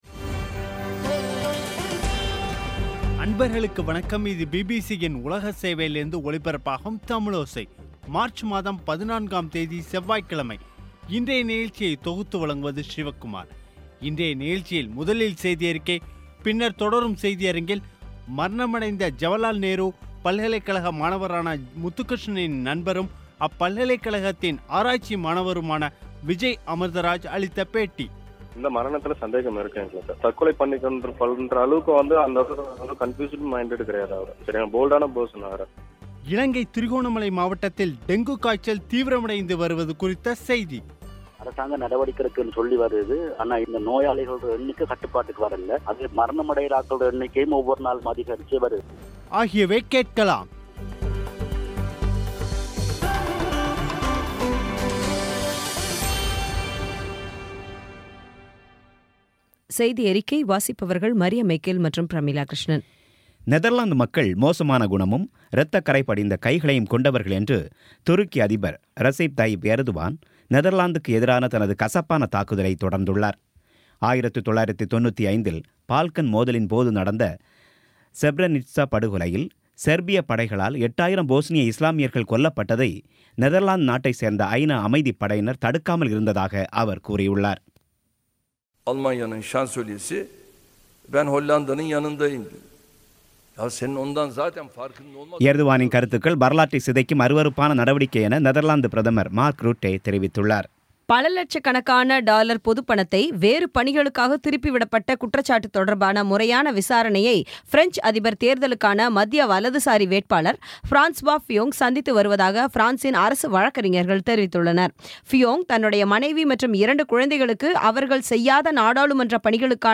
இன்றைய நிகழ்ச்சியில் முதலில் செய்தியறிக்கை, பின்னர் தொடரும் செய்தியரங்கில்